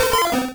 Cri de Tartard dans Pokémon Rouge et Bleu.